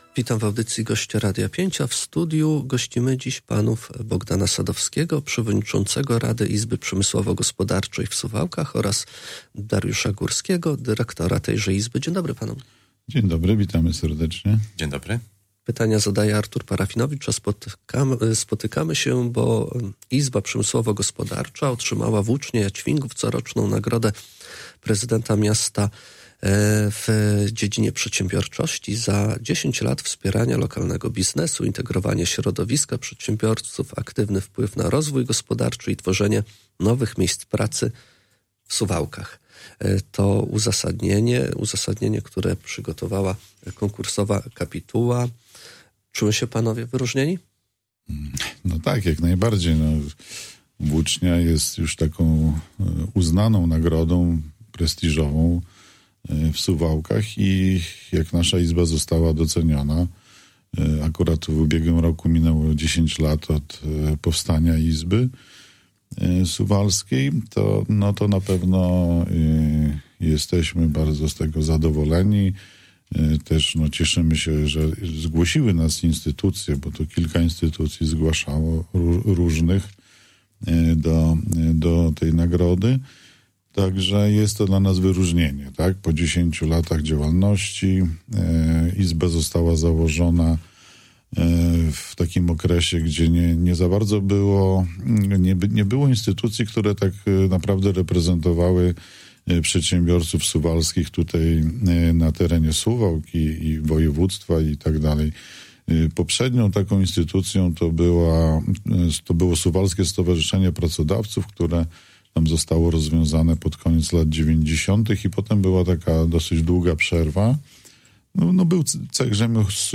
Cała rozmowa poniżej: https